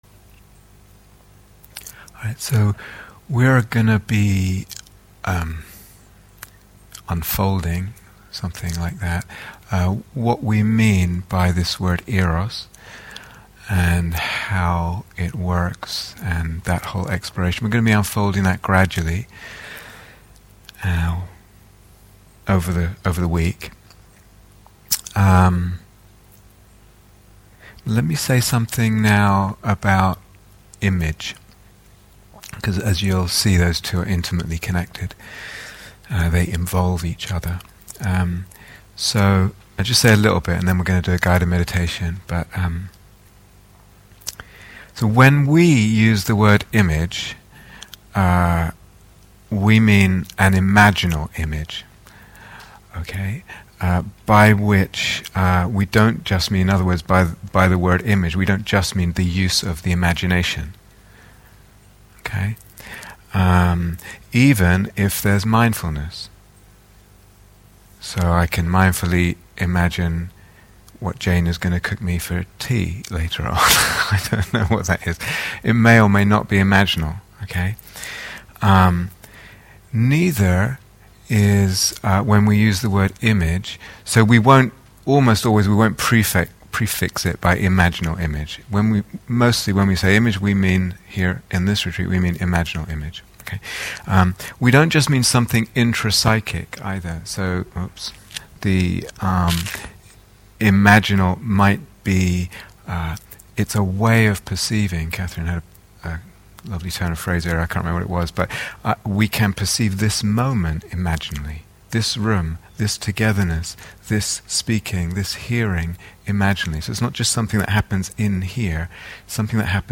Eros in the Imaginal (Guided Meditation)